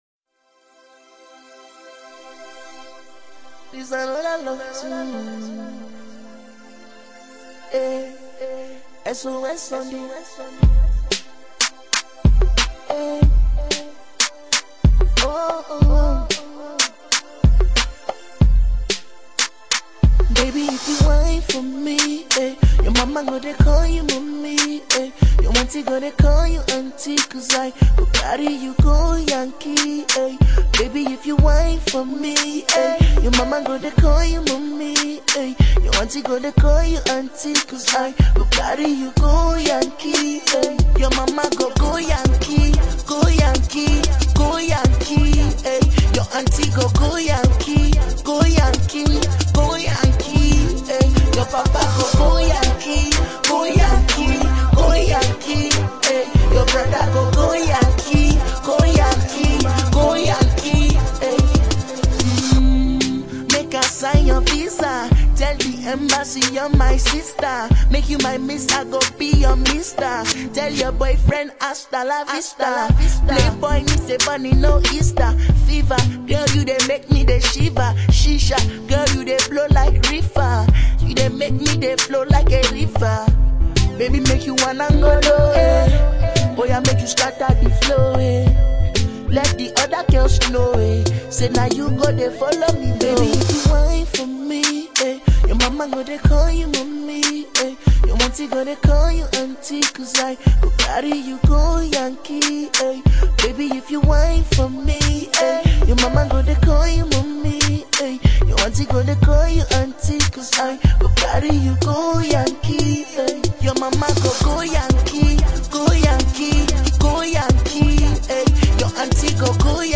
soft-voiced crooner